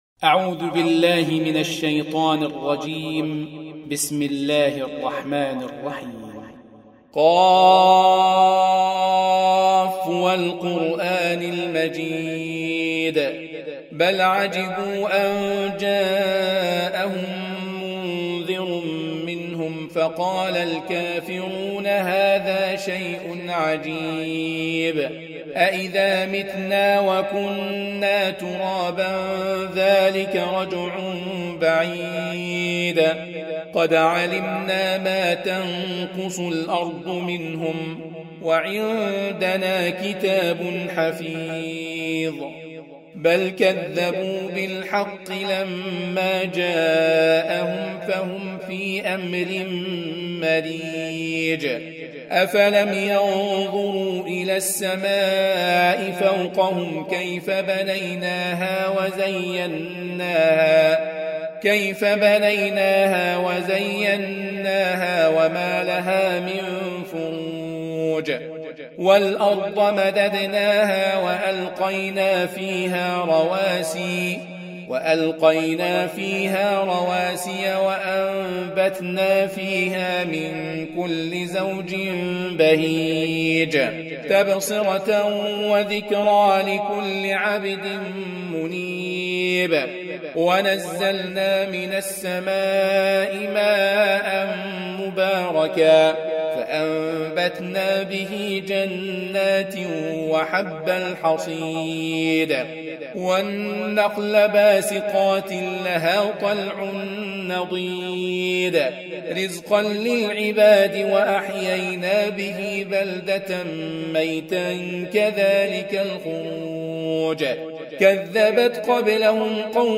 50. Surah Q�f. سورة ق Audio Quran Tarteel Recitation
Surah Repeating تكرار السورة Download Surah حمّل السورة Reciting Murattalah Audio for 50. Surah Q�f. سورة ق N.B *Surah Includes Al-Basmalah Reciters Sequents تتابع التلاوات Reciters Repeats تكرار التلاوات